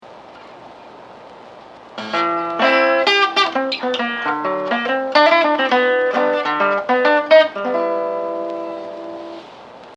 combine chords and single string fills over the four bar chord progression: Am/// D7/// G/// G///